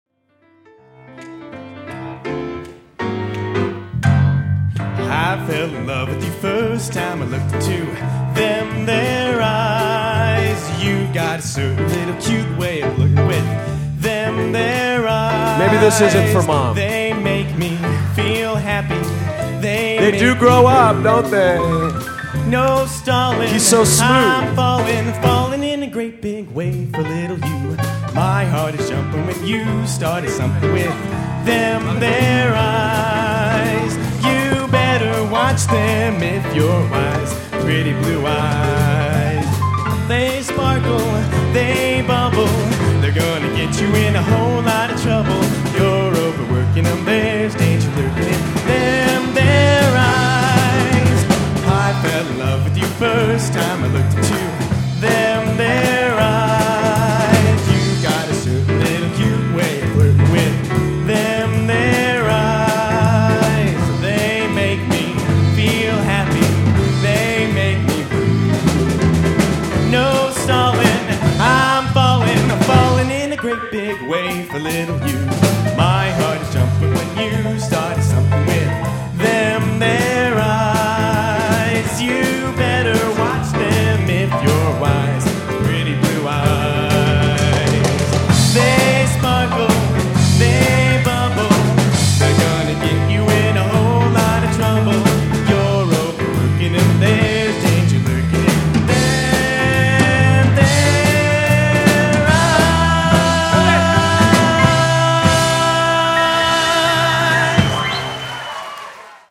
Collection: Northrop High School 10/21/2001
Location: Northrop High School, Fort Wayne, Indiana